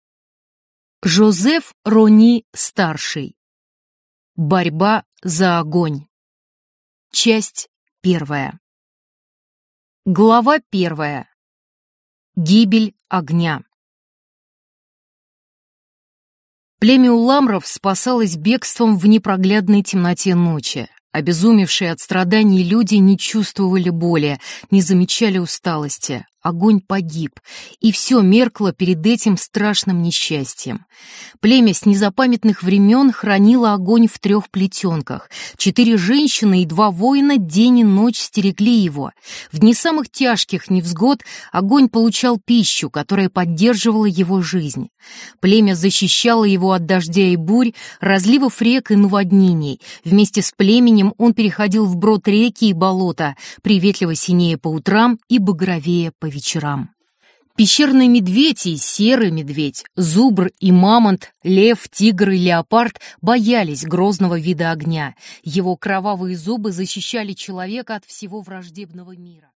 Аудиокнига Борьба за огонь | Библиотека аудиокниг
Прослушать и бесплатно скачать фрагмент аудиокниги